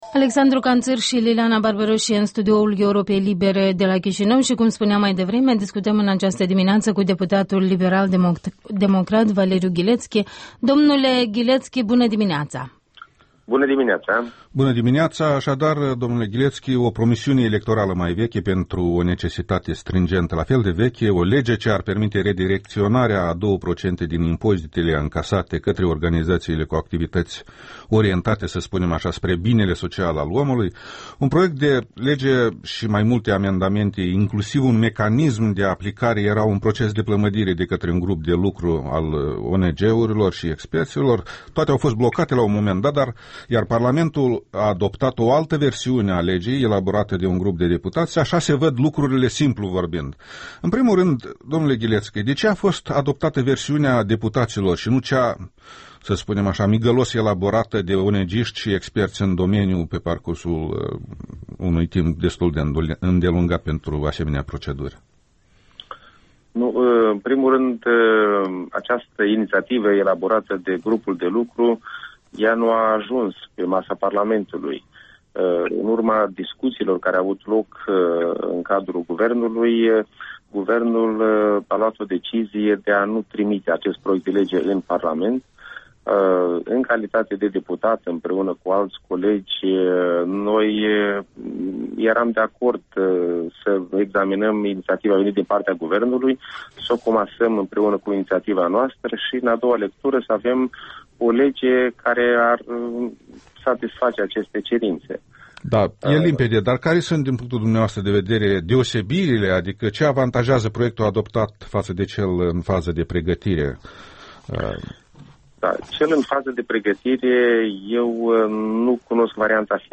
Un interviu cu deputatul PLD, Valeriu Ghilețchi.